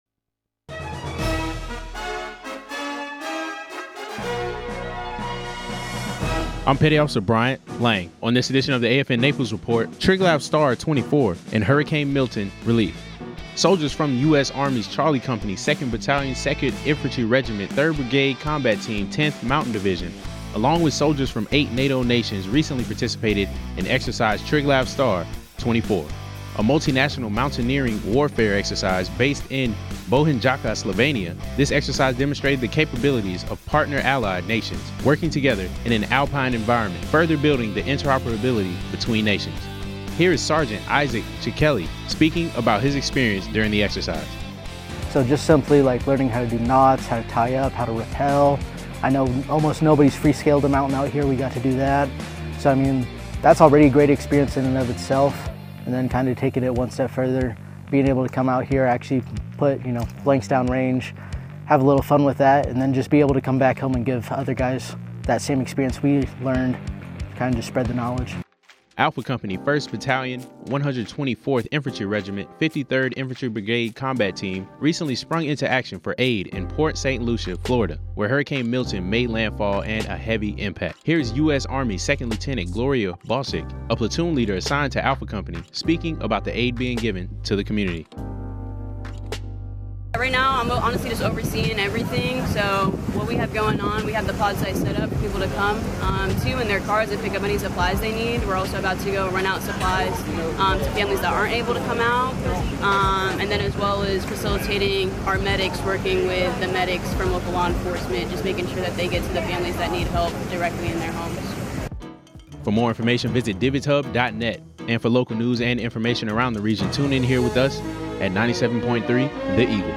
Regional news highlighting Triglav Star and Hurricane Milton relief in Florida.